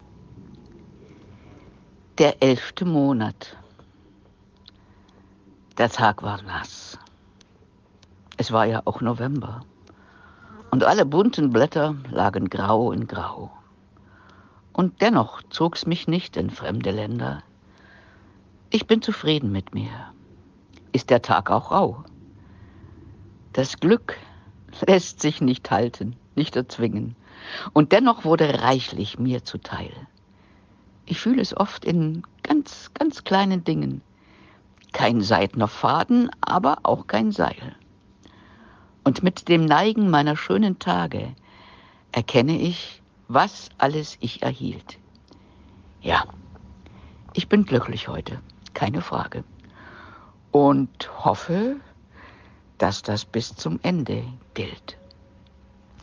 Gedicht
Hier werden einige Gedichte und Lyrische Texte vorgetragen, die auf der Facebookseite Gedichtezauber vorgestellt wurden.